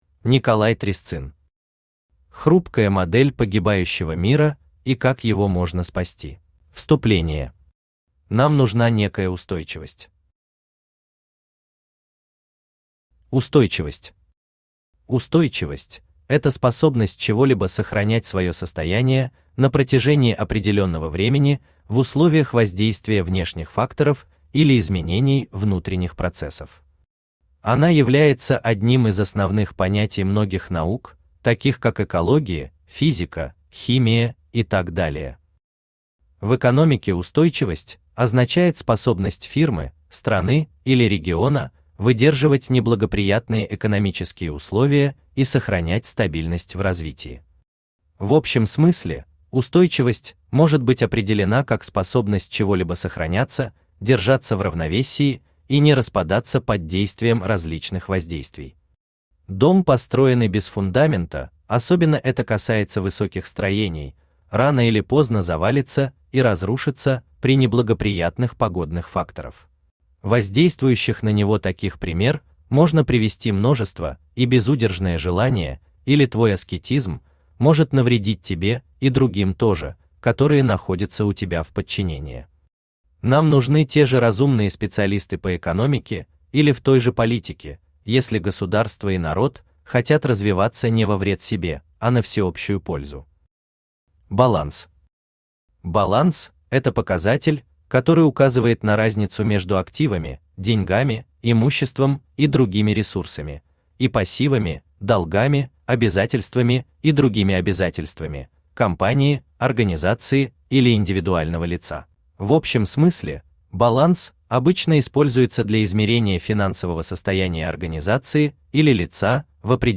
Аудиокнига Хрупкая модель погибающего мира и как его можно спасти | Библиотека аудиокниг
Aудиокнига Хрупкая модель погибающего мира и как его можно спасти Автор Николай Трясцын Читает аудиокнигу Авточтец ЛитРес.